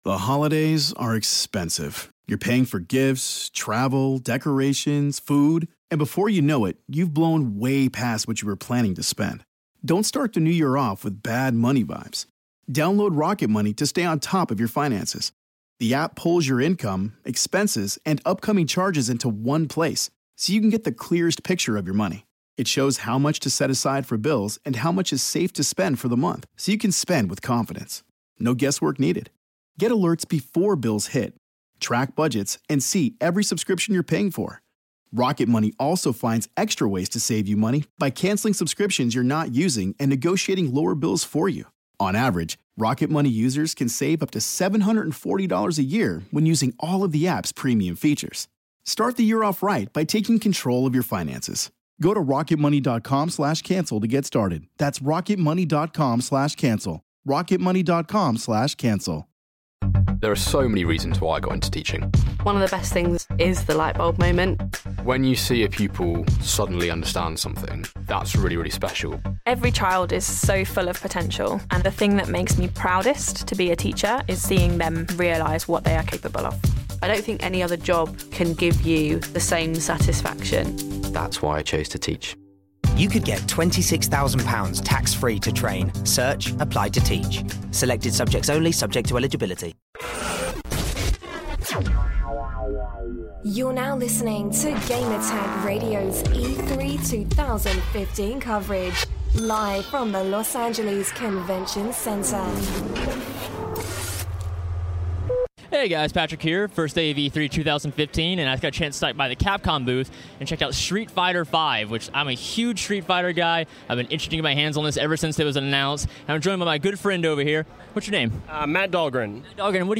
E3 2015: Street Fighter 5 Interview